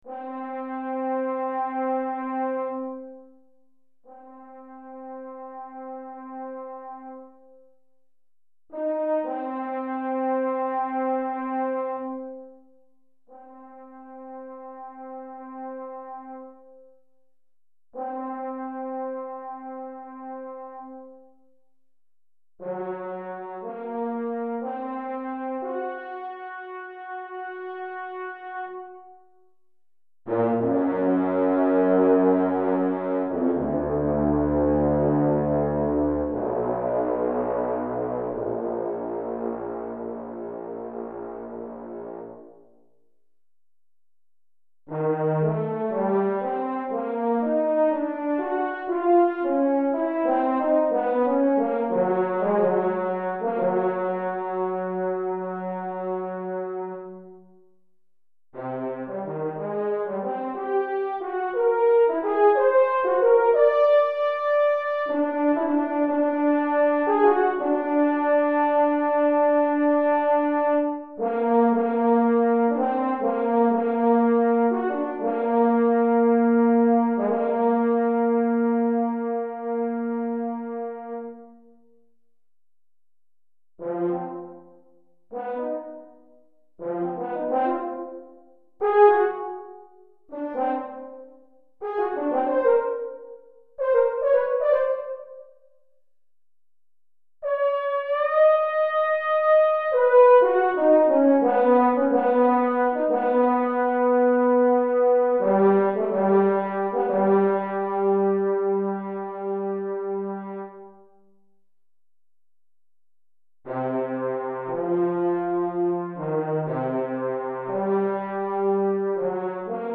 Pour cor solo DEGRE FIN de CYCLE 2
Cor solo